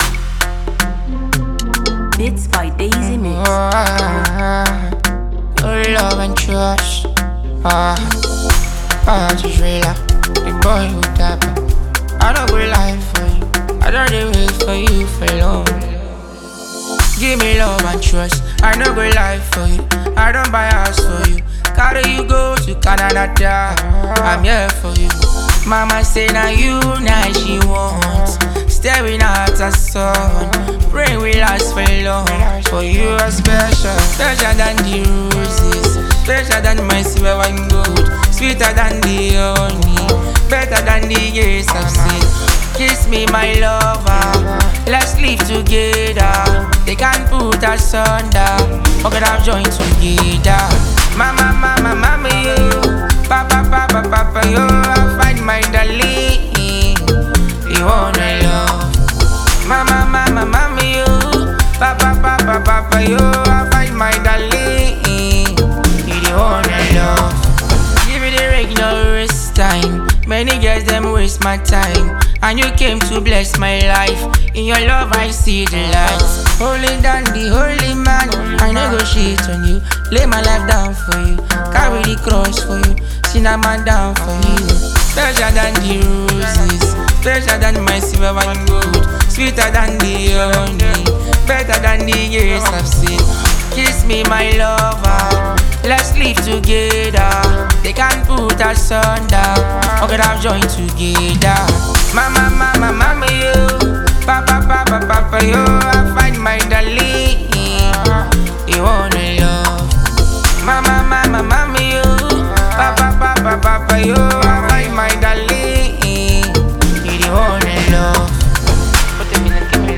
thoughtful Afrobeats single
blends classic energy with purposeful lyricism